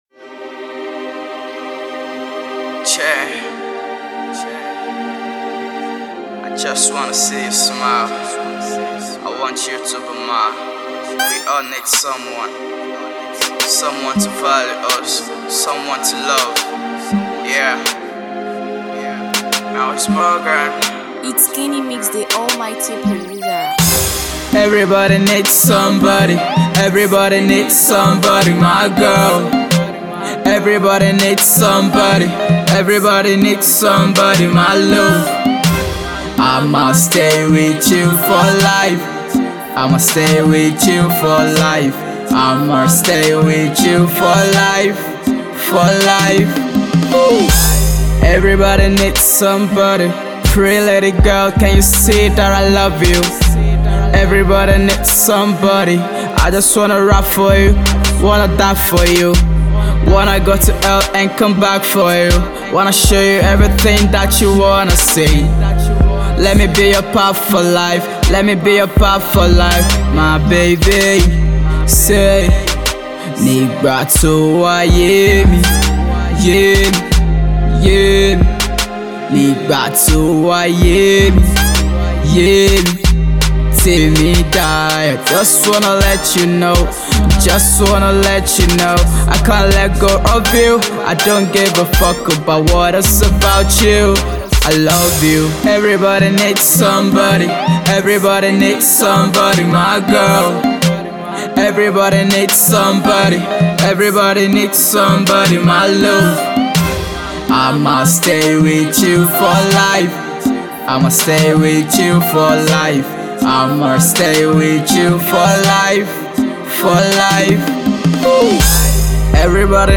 soul-appealing melody